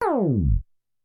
tape_slow9
252basics halt porta roland screech slow stop tape sound effect free sound royalty free Memes